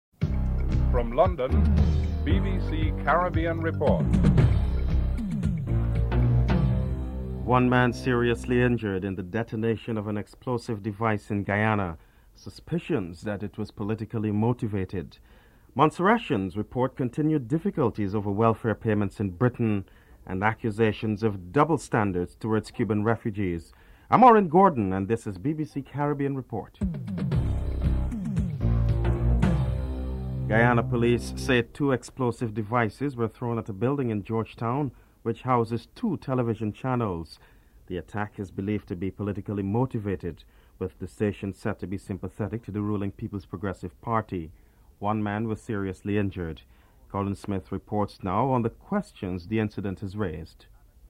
1. Headlines
4. Prime Minister Keith Mitchell makes a formal address to the nation, his first for the year (06:39-08:15)